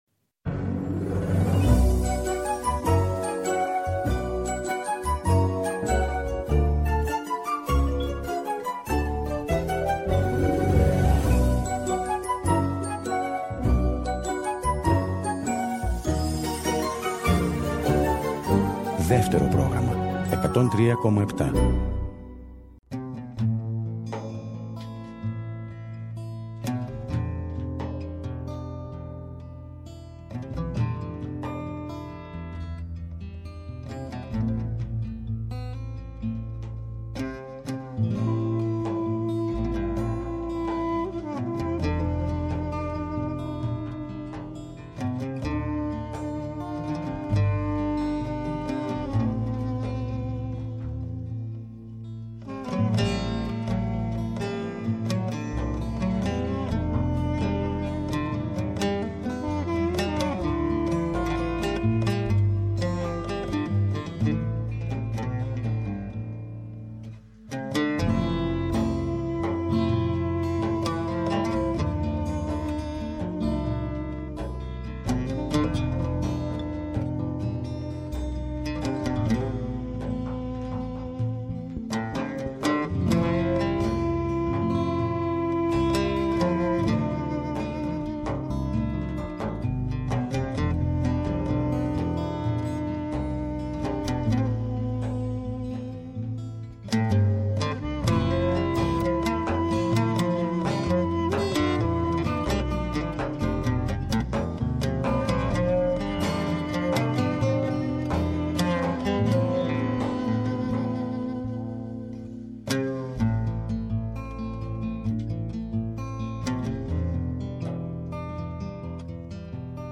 Εκπομπές Μουσική